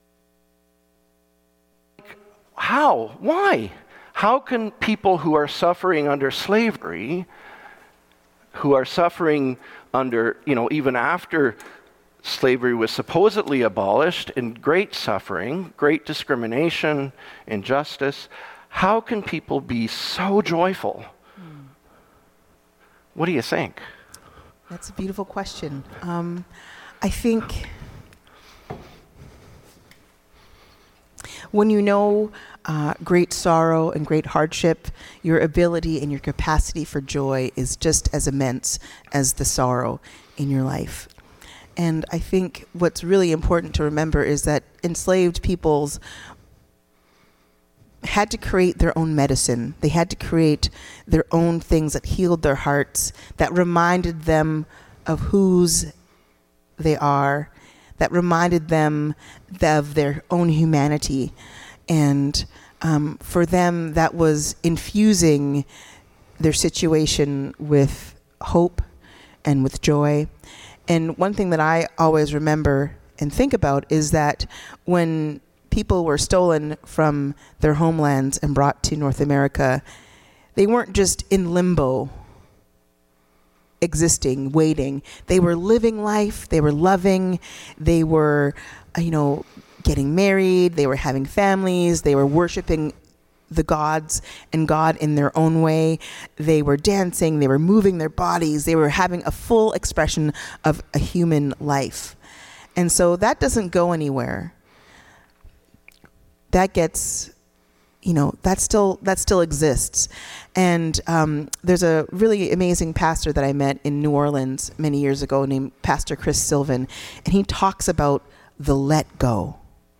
Gospel Worship
with GPUC Band & Choir